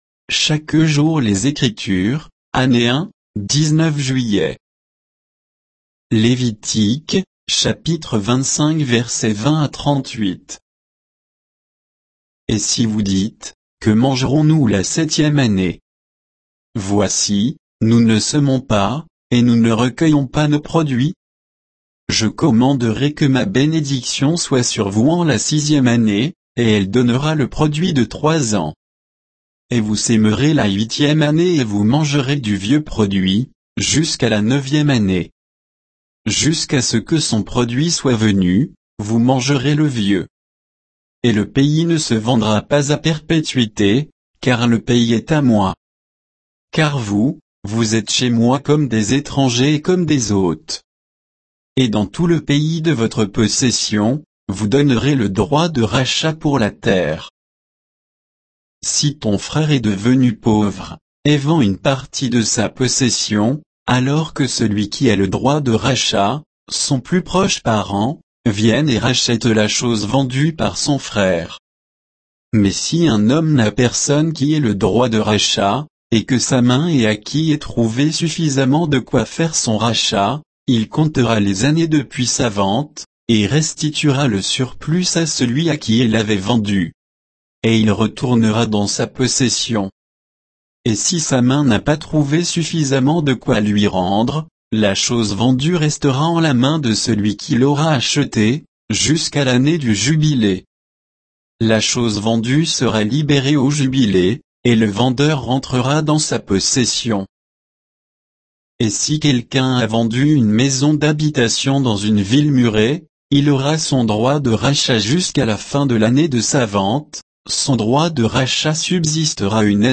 Méditation quoditienne de Chaque jour les Écritures sur Lévitique 25, 20 à 38